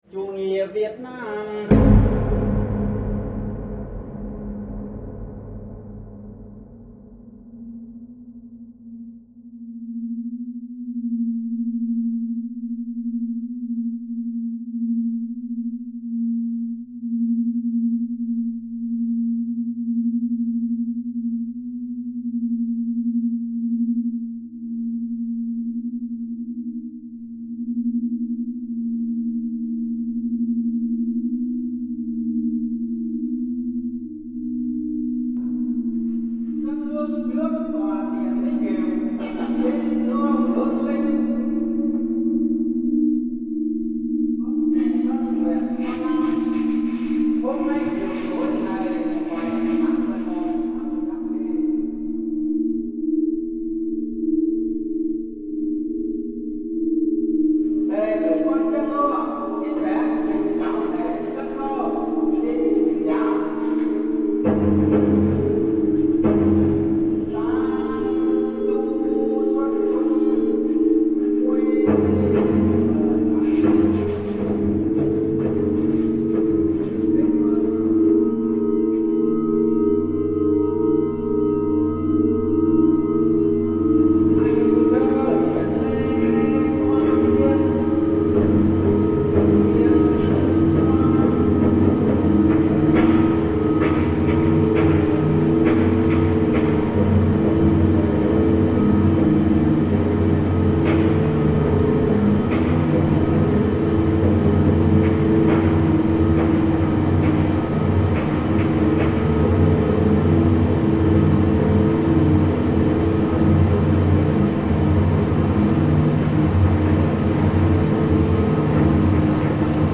Funeral soundscape in Vietnam reimagined